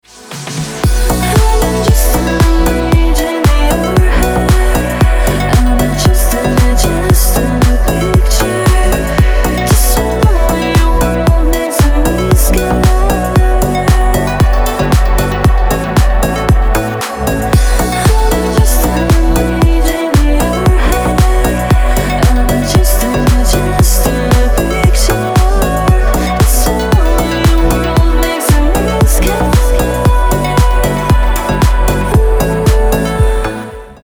• Качество: 320, Stereo
громкие
deep house
мелодичные